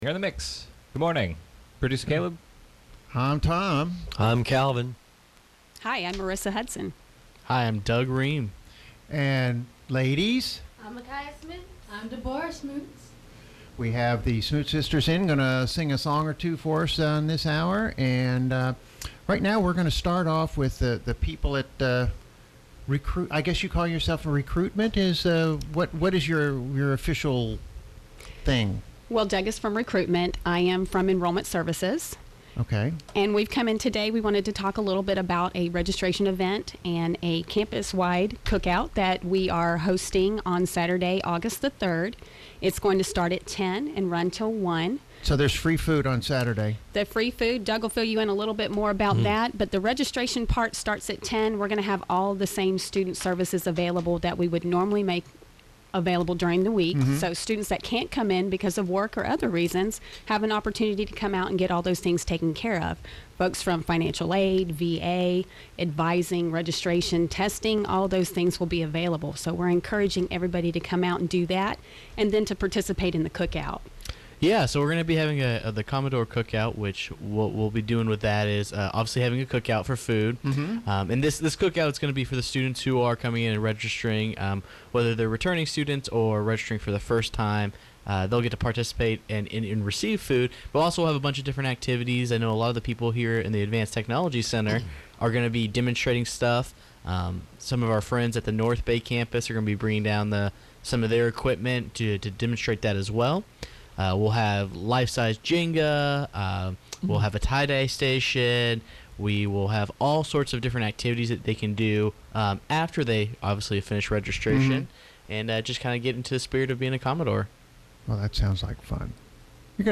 WKGC Studio – The show was packed this morning on The Morning Mix with guest from the college to talk about an event coming up called Super Saturday at Gulf Coast State College?